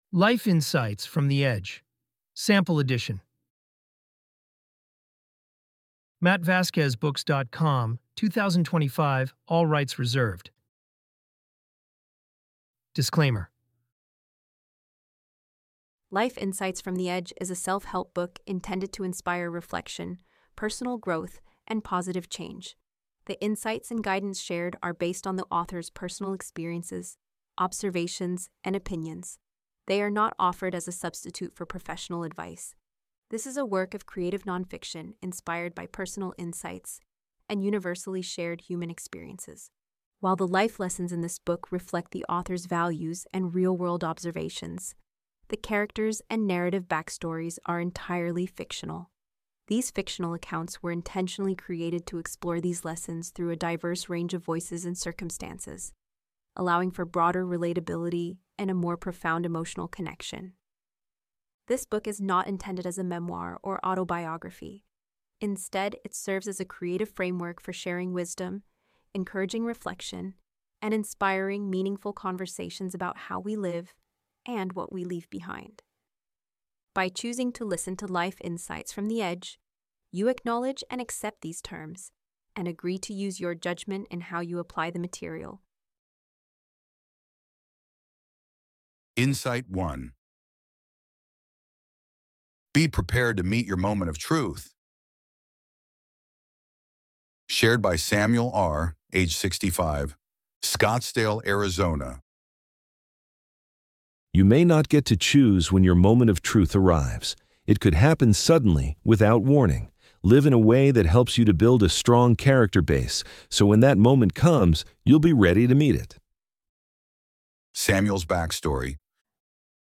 FREE Audiobook